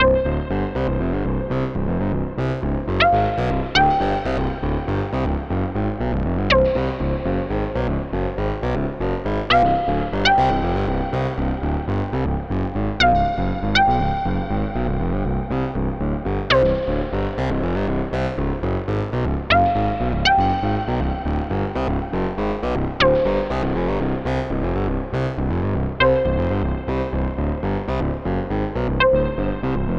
The uxn program controls two sequences simultaneously. The first sequence controls a 7/8 bass line groove, and at each bar randomly chooses a new measure. The second sequence controls a sparse melody on top. It picks one of 2 pre-composed sequences based on what the first sequence chose.